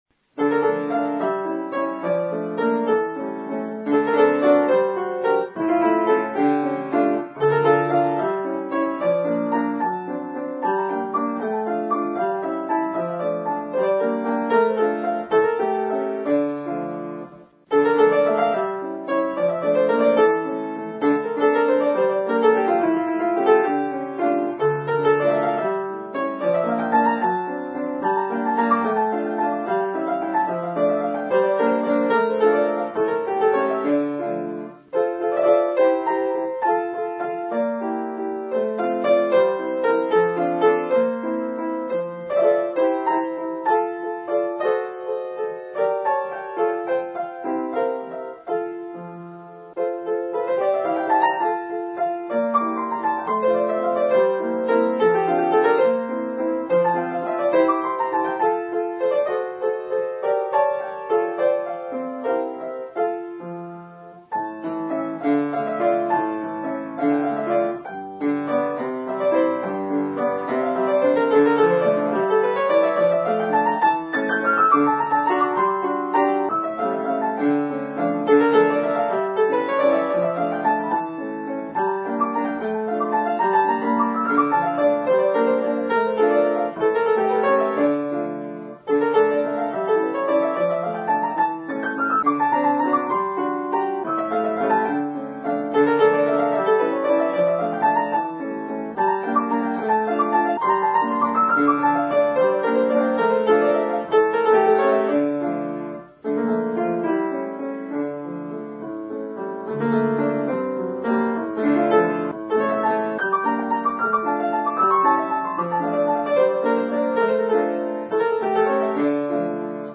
Piano Recital: